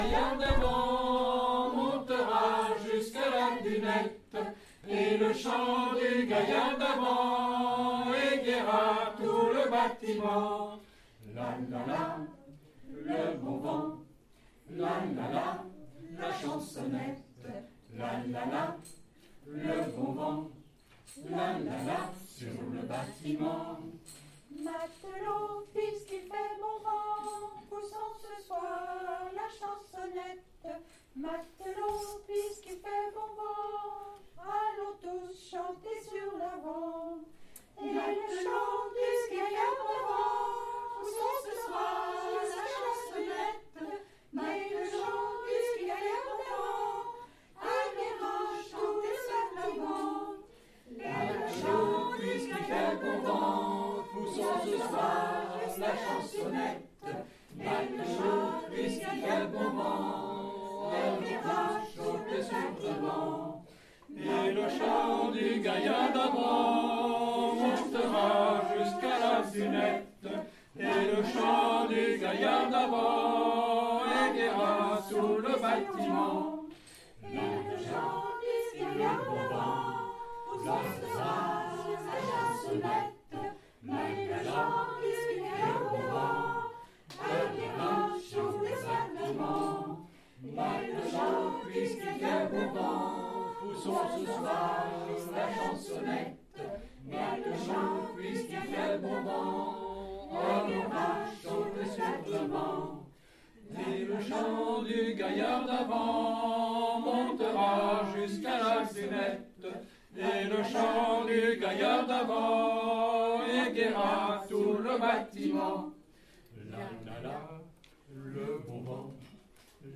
Cess'tival 2025 le 21 juin à Cesson-Sévigné à la médiathèque du Pont des Arts